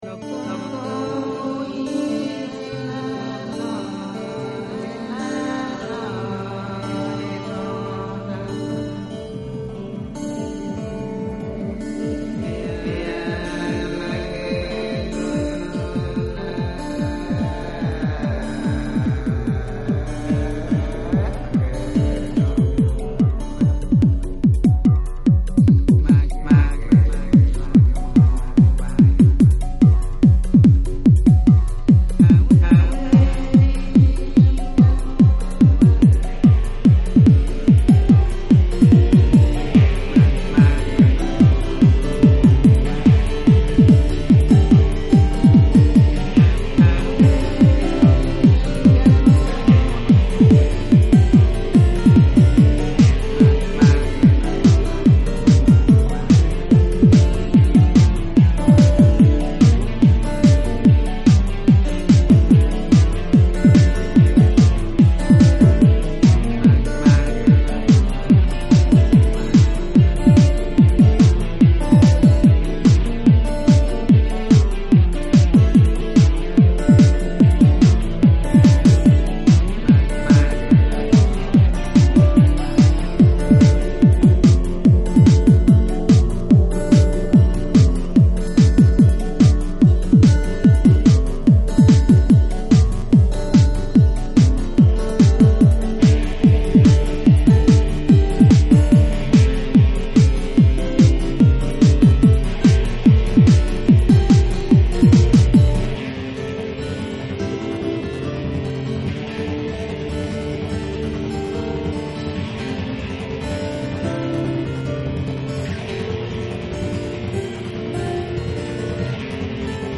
Etiquetes: tekno